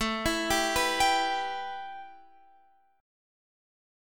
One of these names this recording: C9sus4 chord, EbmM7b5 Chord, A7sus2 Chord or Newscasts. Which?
A7sus2 Chord